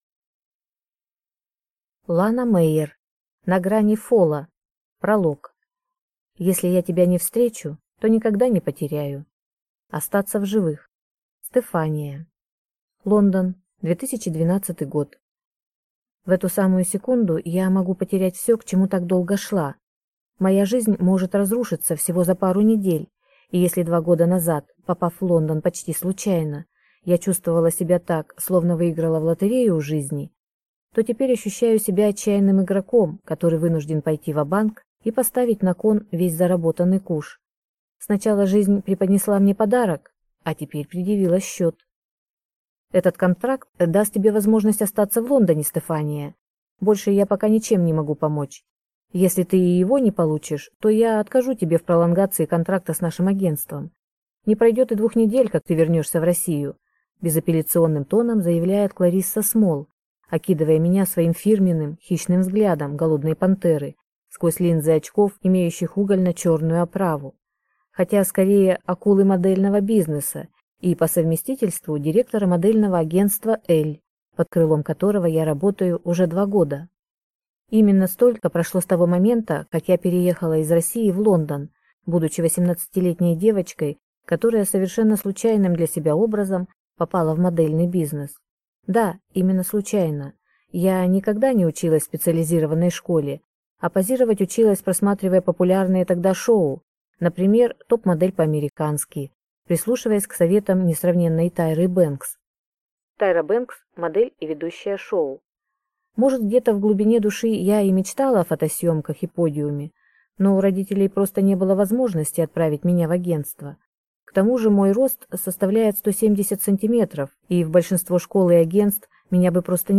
Aудиокнига На грани фола